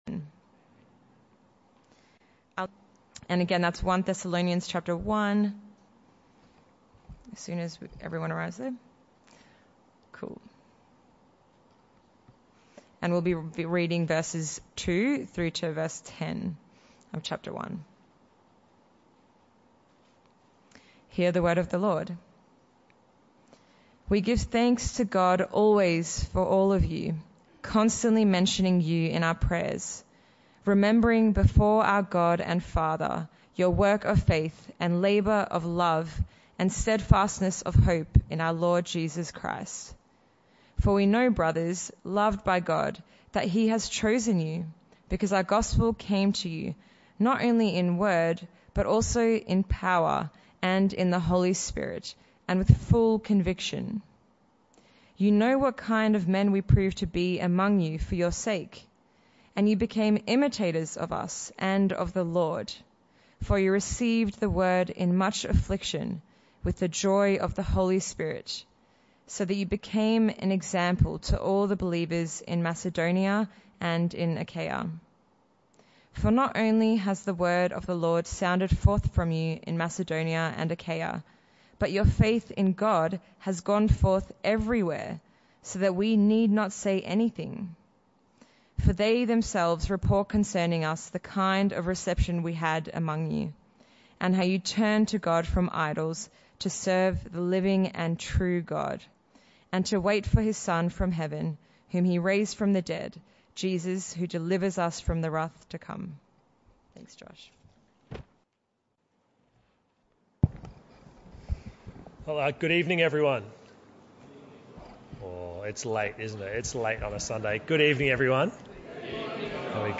This talk was part of the AM & PM Sermon series entitled 5 Signs Of A Healthy Christian.